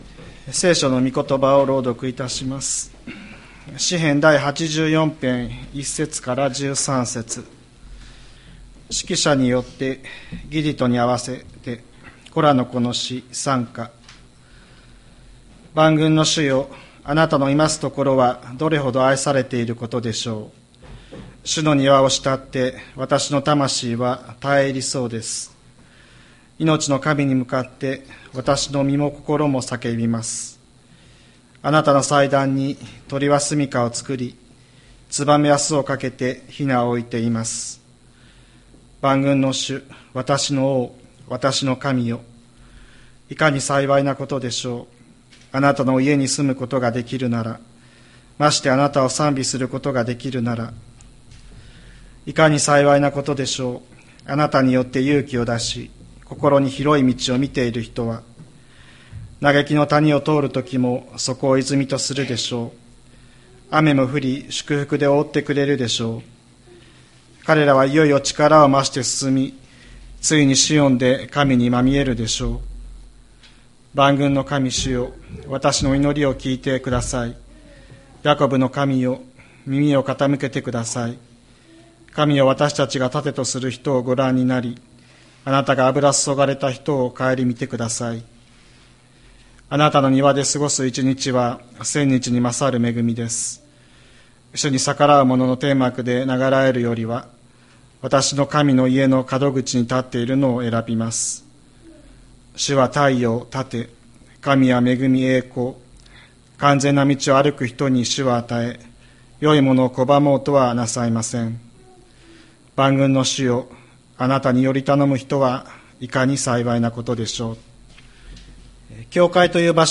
2025年01月12日朝の礼拝「わたしたちの慕うところ」吹田市千里山のキリスト教会
千里山教会 2025年01月12日の礼拝メッセージ。